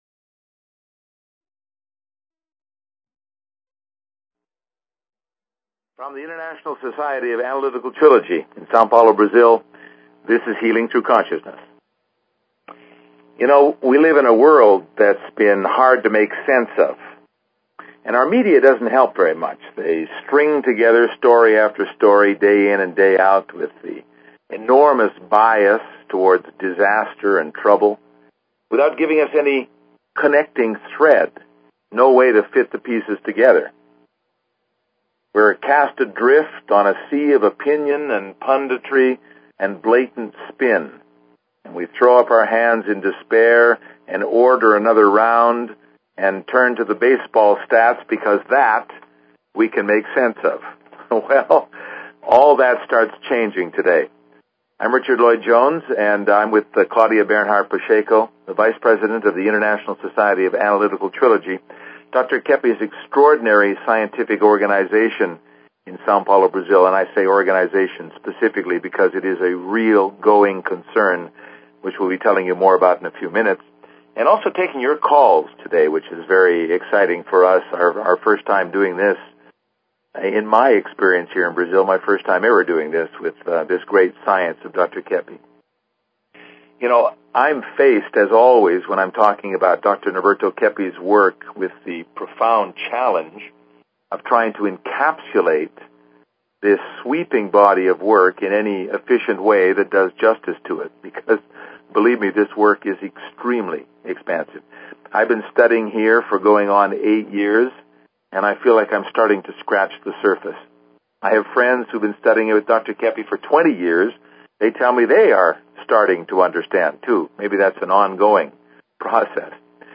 Talk Show Episode, Audio Podcast, Healing_Through_Consciousness and Courtesy of BBS Radio on , show guests , about , categorized as
An overview of Keppe's vast science of Analytical Trilogy, including a call-in question from a listener about kids and responsibility, and a LIVE report from the Eco-German trade show about the revolutionary Keppe Motor.